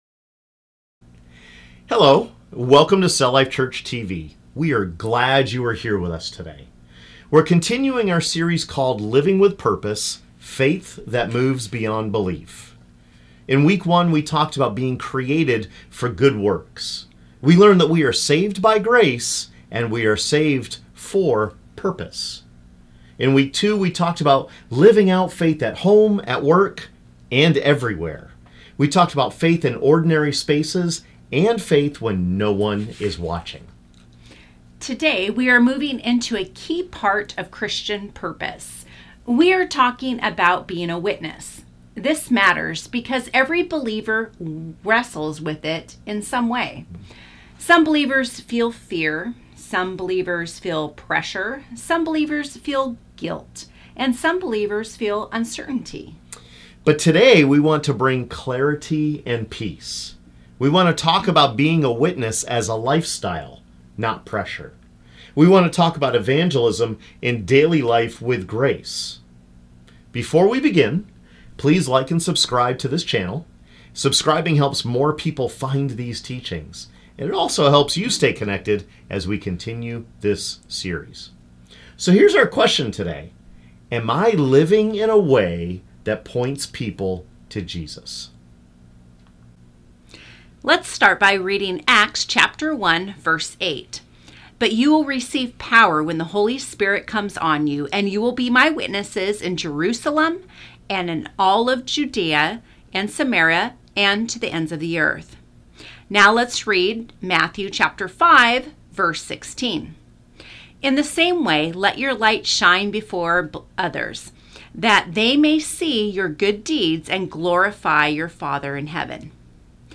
Acts 1:8 and Matthew 5:16 show that witness for Jesus is a Spirit-empowered lifestyle, not pressure. In this Week 3 teaching, we focus on evangelism in daily life through a visible faith that serves, loves, and speaks when God opens the door.